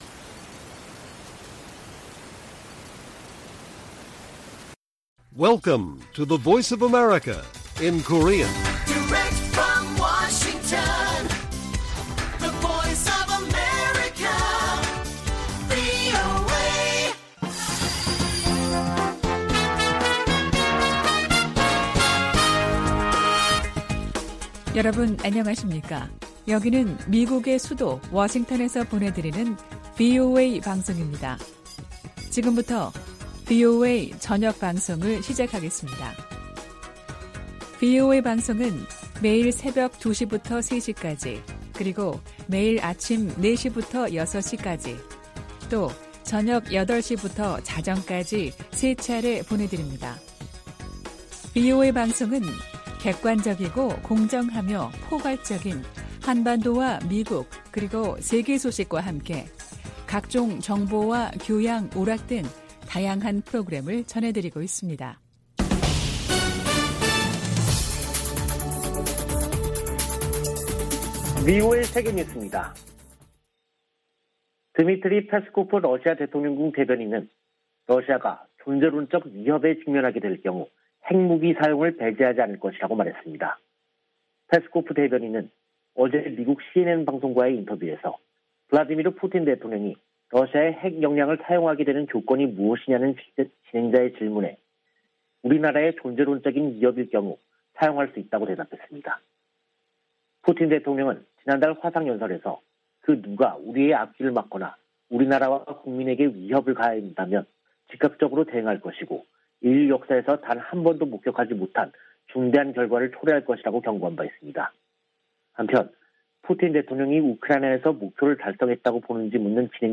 VOA 한국어 간판 뉴스 프로그램 '뉴스 투데이', 2022년 3월 23일 1부 방송입니다. 북한 해킹 조직이 러시아 등 사이버 범죄자들과 협력하고 있다고 백악관 국가안보보좌관이 지적했습니다. 독자 대북제재를 강화하고 있는 조 바이든 미국 행정부는 지난 3개월간 20건이 넘는 제재를 가했습니다. 유엔 인권기구가 49차 유엔 인권이사회 보고에서 회원국들에 국내 탈북민들과 접촉하고 인권 유린 책임을 규명할 수 있게 보장해 줄 것을 촉구했습니다.